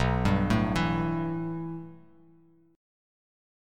B7sus4#5 Chord
Listen to B7sus4#5 strummed